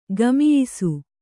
♪ gamiyisu